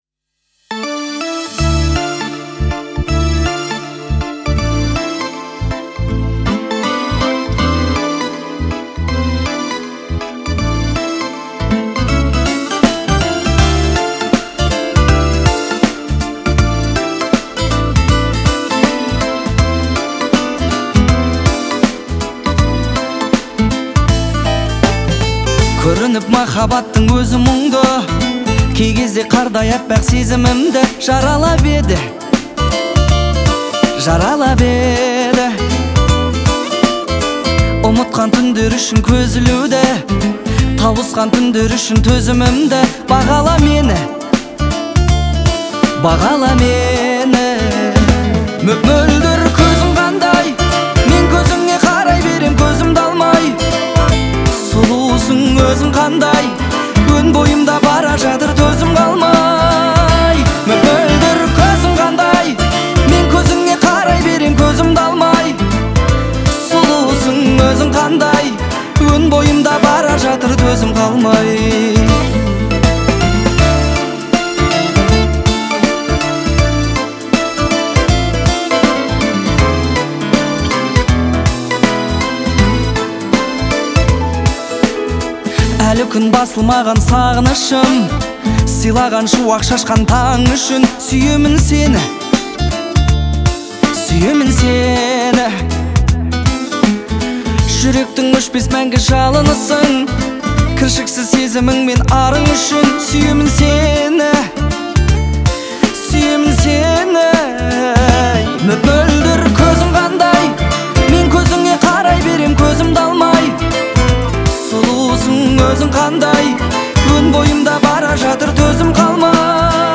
романтическая песня